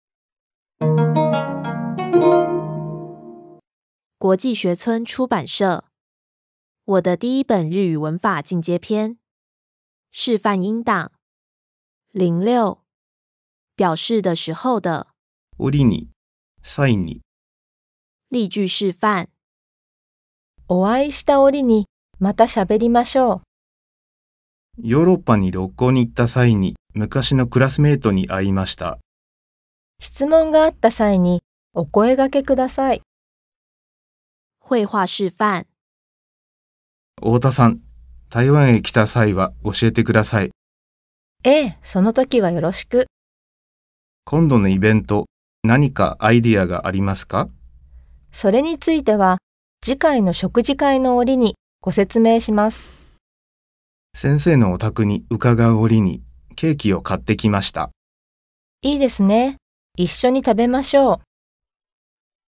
●生動配音讓你練聽力、練口說更有感覺，附贈 QR 碼隨掃隨聽！
本書附上由日語母語者提供的MP3示範音檔，以QR 碼方式提供，可隨書中內容掃描聆聽，免按上下鍵搜尋，快速地讓音檔與內容互相搭配。